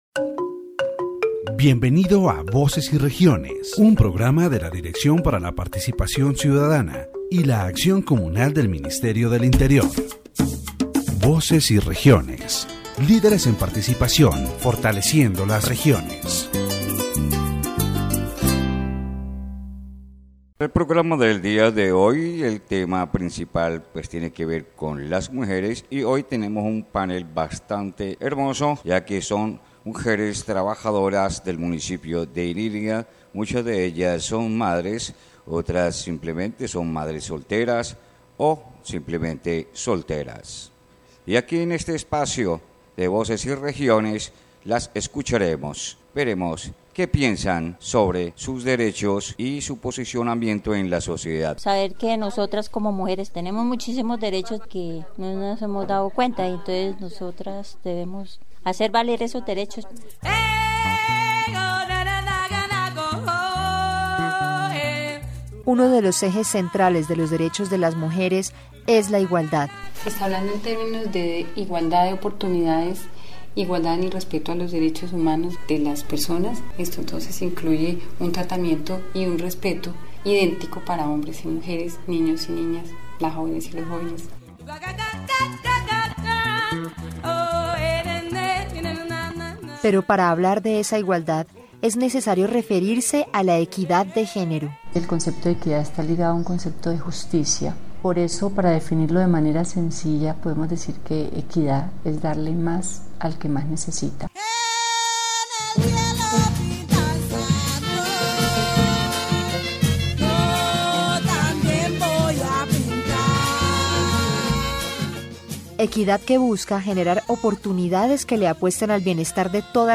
In this edition of Voces y Regiones, several women from the municipality of Inírida, Guainía, share their life experiences as mothers and resilient individuals facing diverse circumstances. Through their testimonies, the program highlights the importance of equality between men and women, emphasizing equity as a principle of justice that ensures women's well-being in all aspects of life.